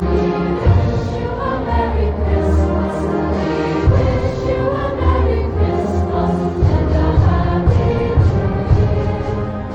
Emporia Public Schools invited the community to White Auditorium for its annual musical holiday gift this week.
The district held its two-day Seasonal Celebration on Wednesday and Thursday, with Emporia Middle School and Emporia High students performing alongside an Alumni Choir. As normal, White Auditorium was full both nights for the performances.
This was the 90th rendition of the school district’s holiday concert.